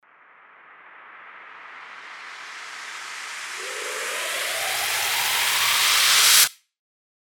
FX-908-RISER
FX-908-RISER.mp3